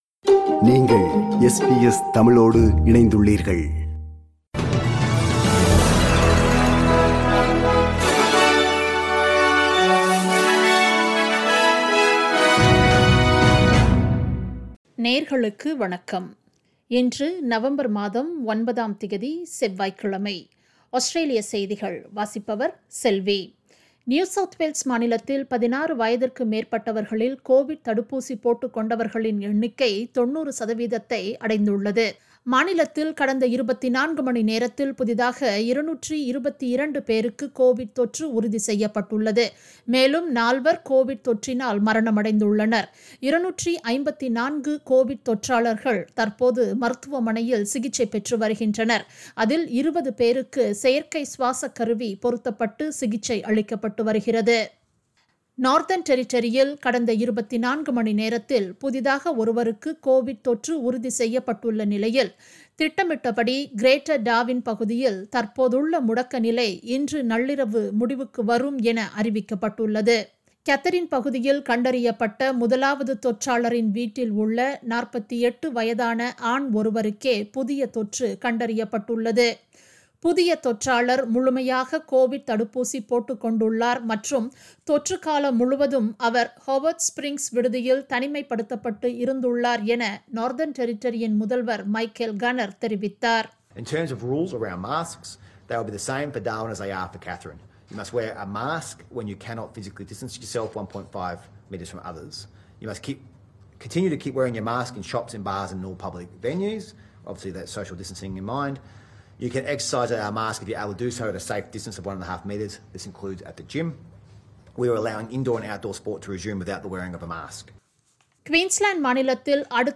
Australian news bulletin for Tuesday 09 November 2021.